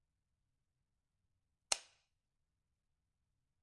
Gun Toy Reload
描述：Toy gun being reloaded, cocked. Recorded with a Zoom H6 field recorder and stereo microphone. Loopable.
标签： cock pistol shot reload revolver loopable gun weapon handgun fieldrecording OWI toy stereo gunshot
声道立体声